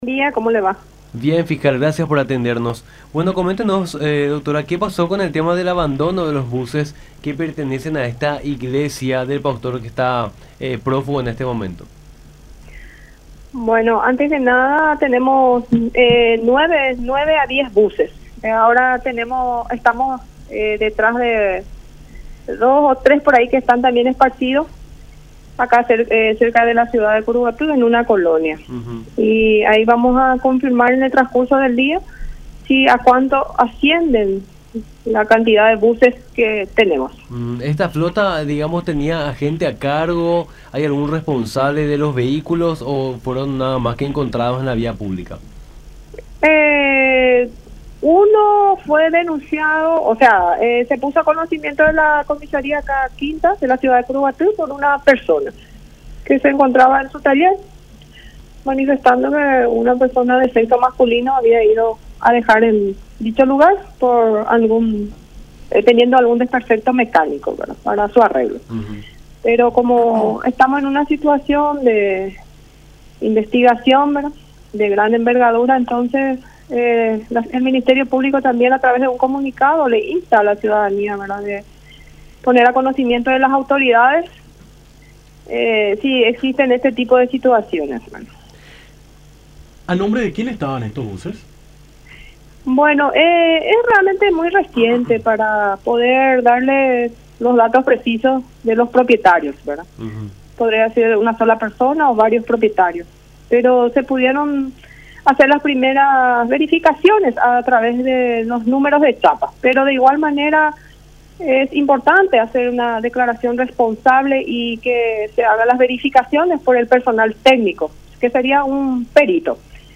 “De momento, son entre nuevo a diez buses los que encontramos, pero estamos detrás de otros tres que estarían por la zona de Curuguaty (…) Esta es una investigación de gran envergadura y luego de estas pesquisas sabremos a cuánto asciende la cantidad de buses. Estos buses quedan a disposición de investigación para el Ministerio Público”, afirmó la fiscal del caso, Elvia Chávez, en conversación con Nuestra Mañana por La Unión.